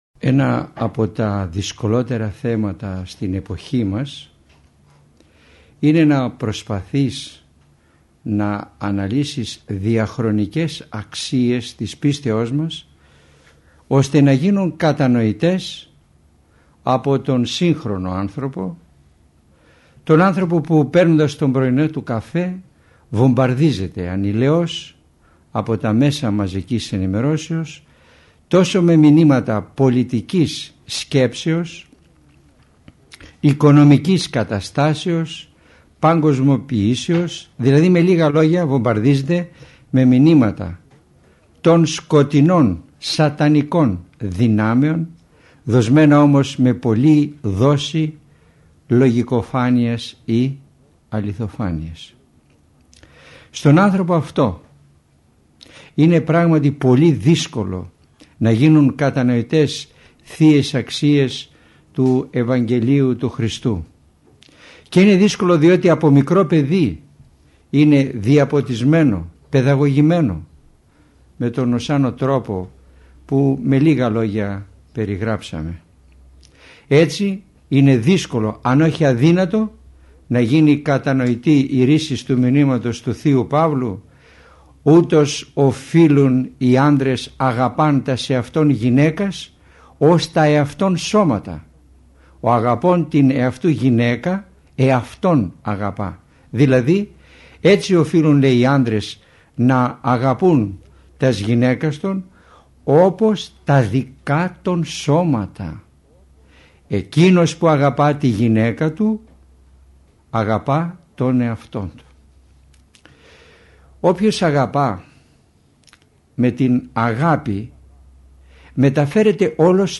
Ακολούθως σας παραθέτουμε ηχογραφημένη ομιλία